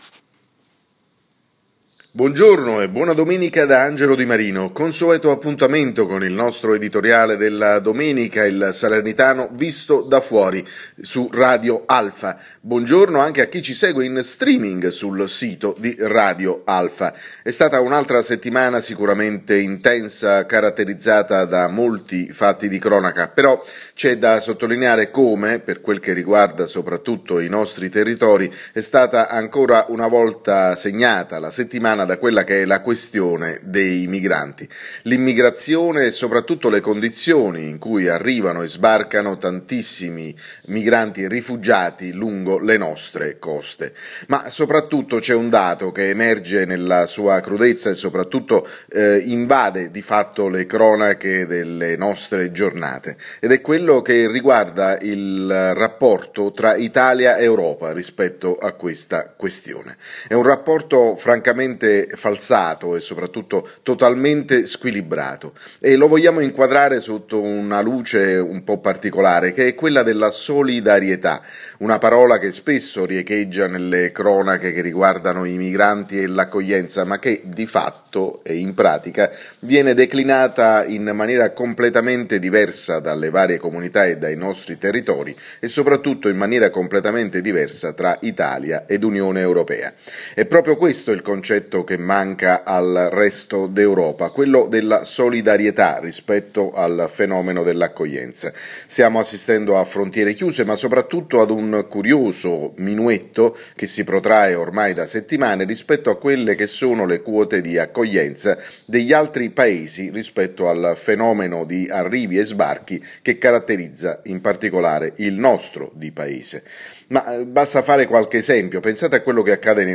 L’editoriale della domenica andato in onda sulle frequenze di Radio Alfa questa mattina.